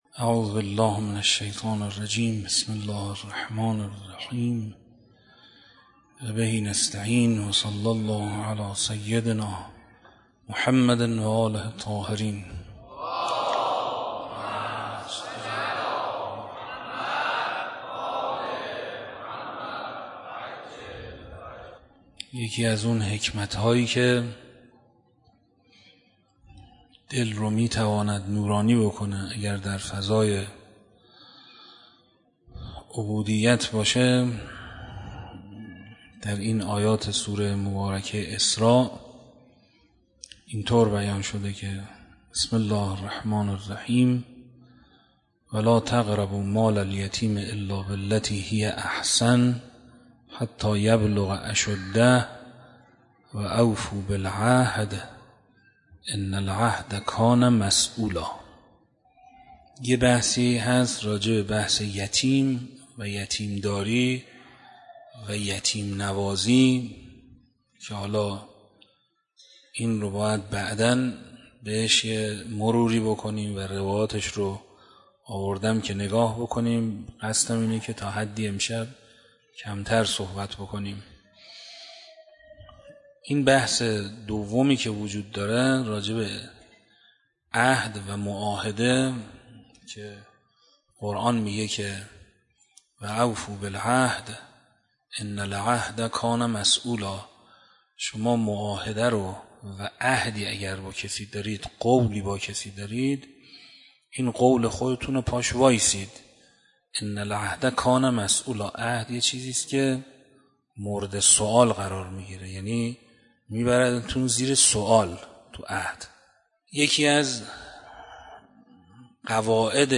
شب عاشورا محرم 96 - مسجد دانشگاه صنعتی شریف